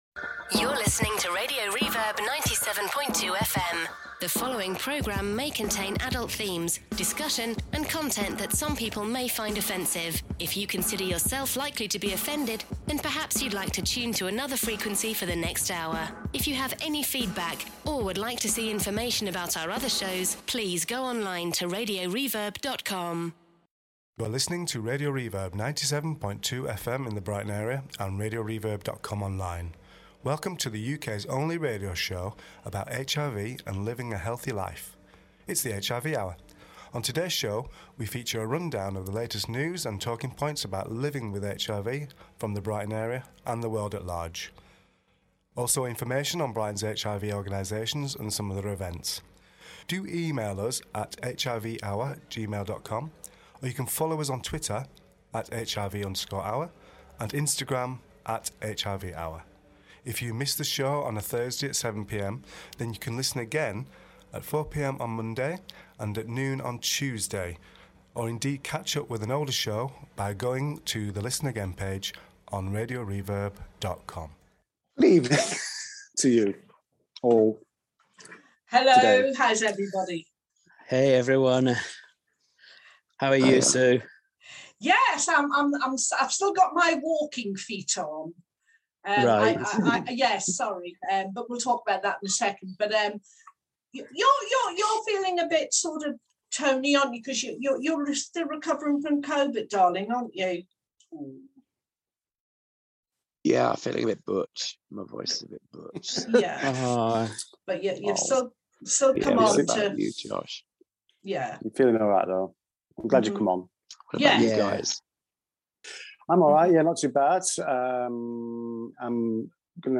talks to participants from the Terrence Higgins Trust Ribbon walk in Brighton. Stories include; HIV stories from around the world and local Community News